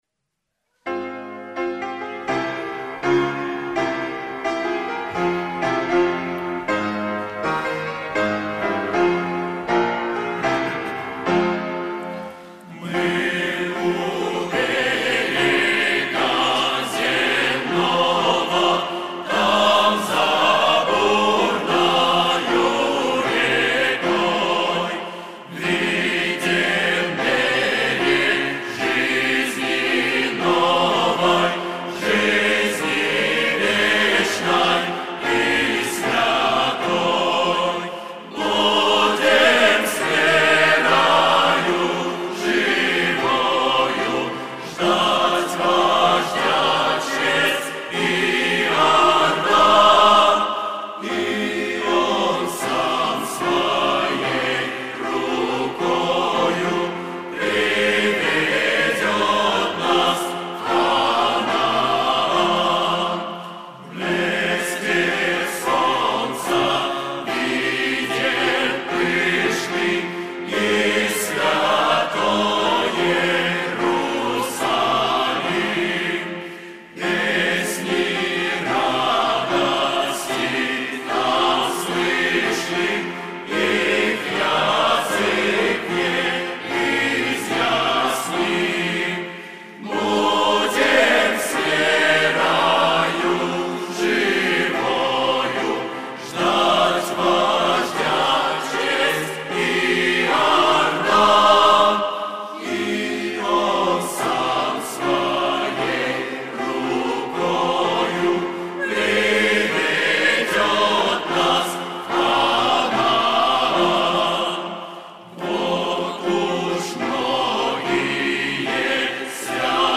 Богослужение 28.09.2024
Мы у берега земного - Хор (Пение)[